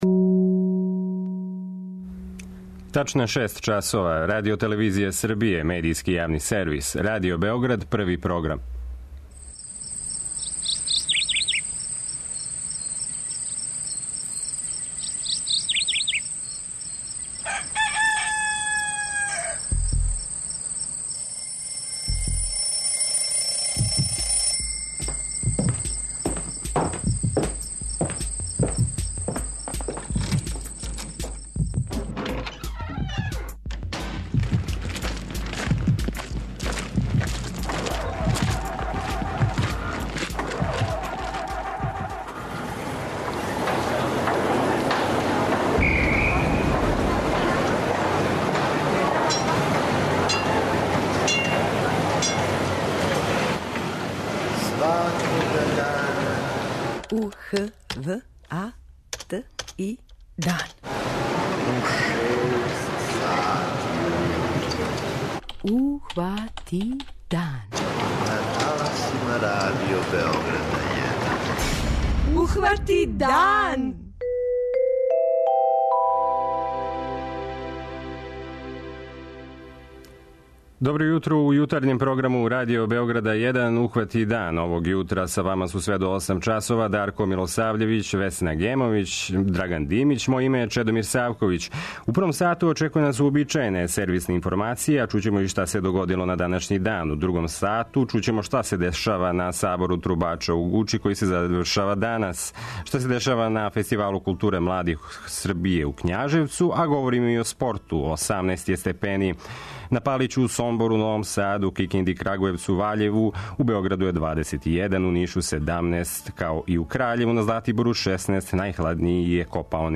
Наш дописник се јавља са Сабора трубача у Гучи.